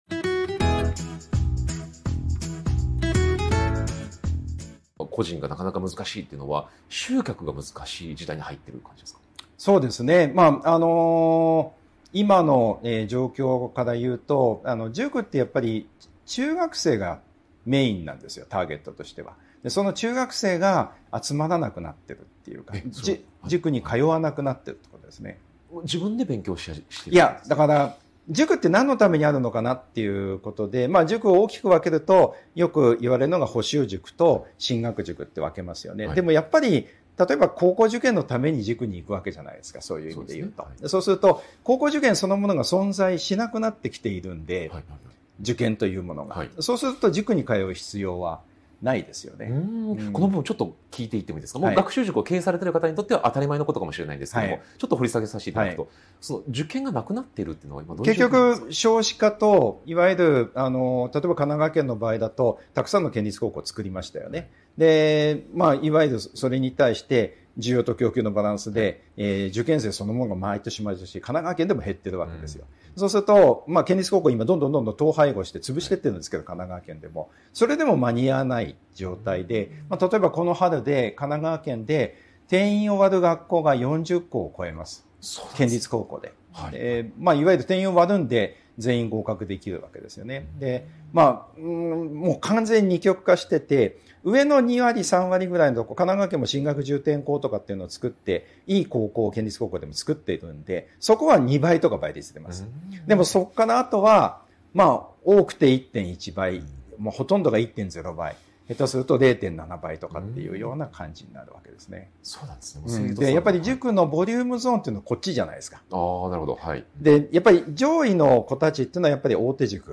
【特別インタビュー】塾業界38年の現場から見える未来の学習塾経営とは